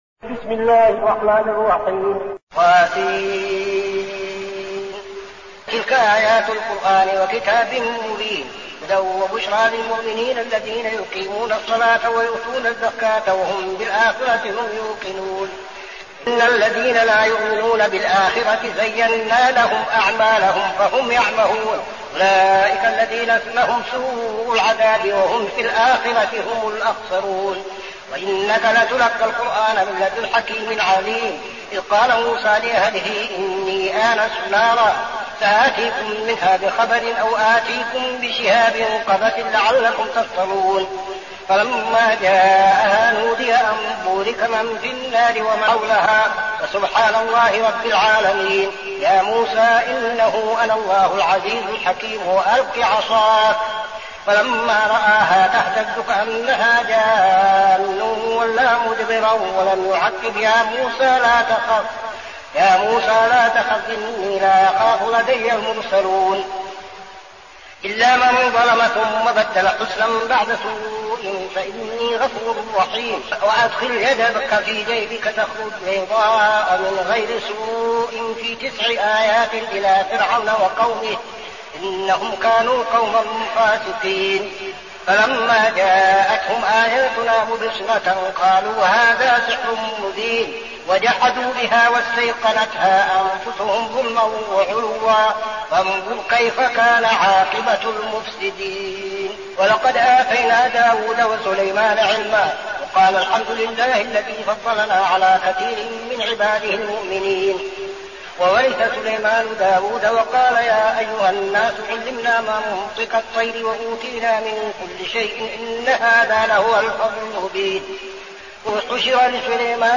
المكان: المسجد النبوي الشيخ: فضيلة الشيخ عبدالعزيز بن صالح فضيلة الشيخ عبدالعزيز بن صالح النمل